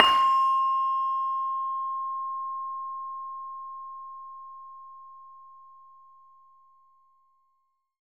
LAMEL C5  -L.wav